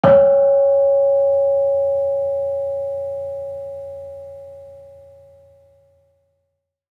Gamelan Sound Bank
Kenong-resonant-C#4-f.wav